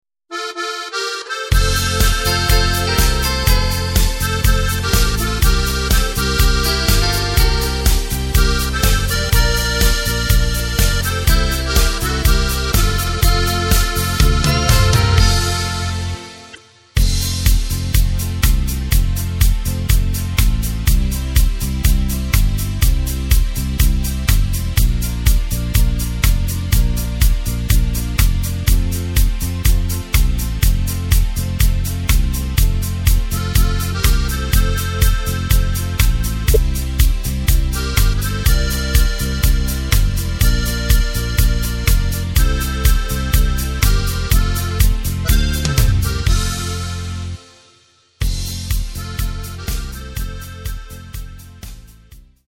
Takt:          4/4
Tempo:         123.00
Tonart:            F
Playback mp3 mit Lyrics